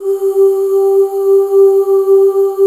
G3 FEM OOS.wav